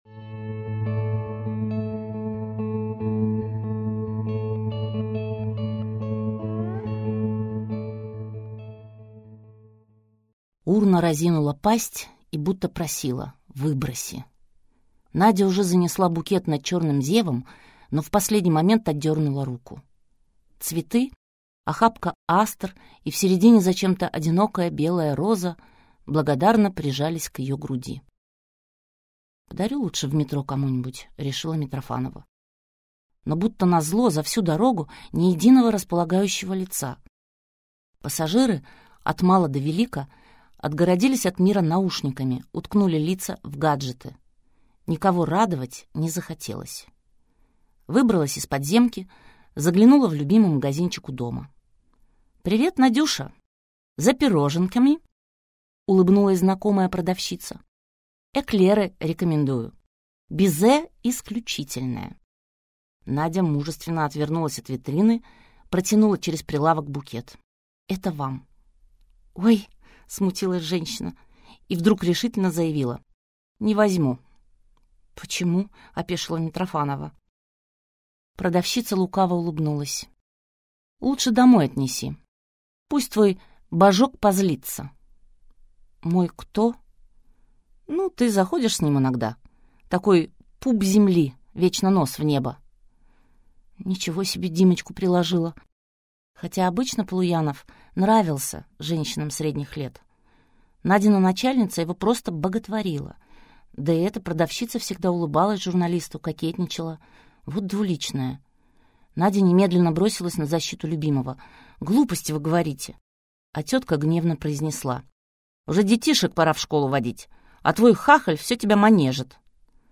Прослушать фрагмент аудиокниги Свадьбы не будет Анна Литвиновы Произведений: 14 Скачать бесплатно книгу Скачать в MP3 Вы скачиваете фрагмент книги, предоставленный издательством